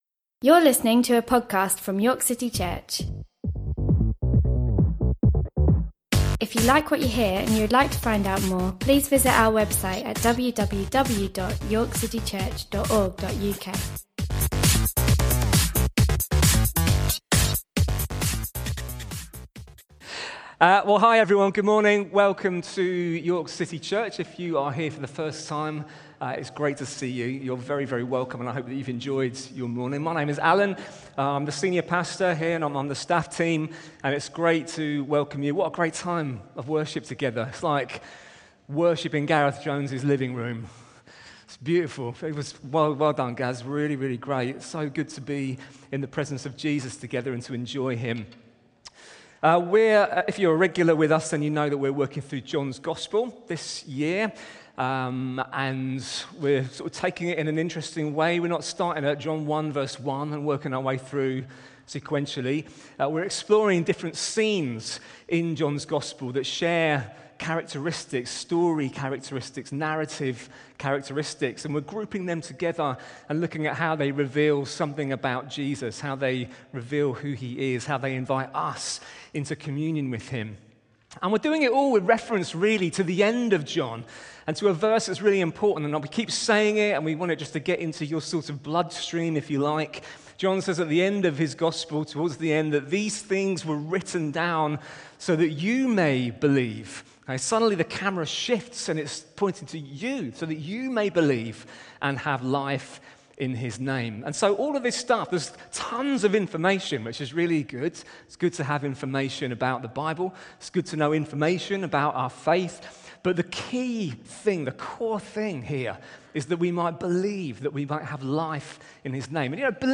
York City Church is a church meeting in the historic city of York. This podcast is primarily a selection of our weekly sermon.